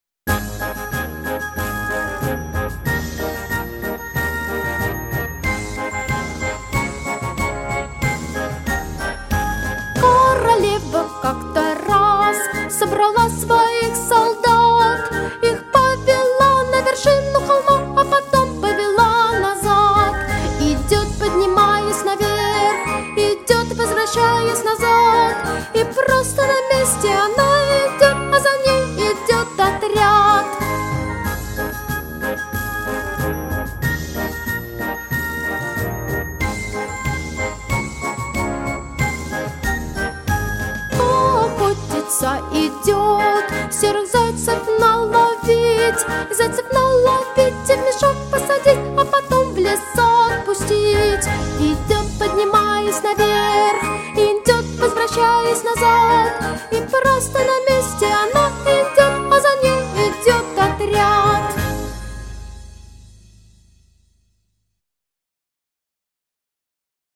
Детский сад